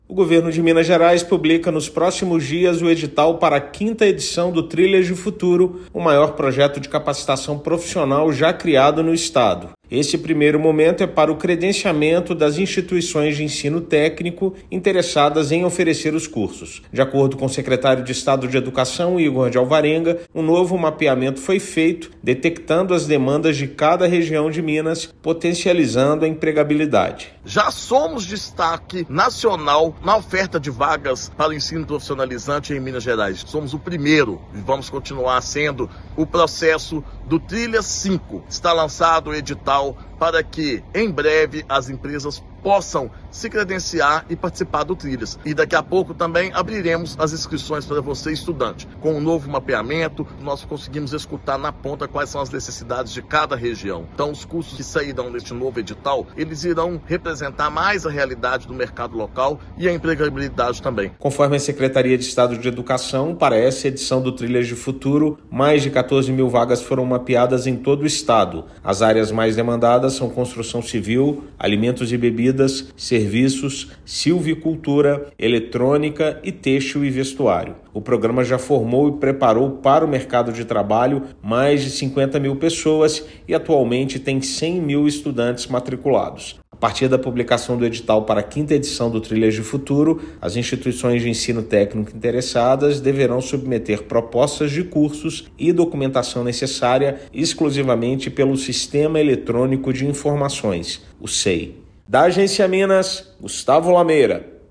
Edital será aberto para instituições de ensino técnico interessadas em participar da quinta etapa do projeto. Ouça matéria de rádio.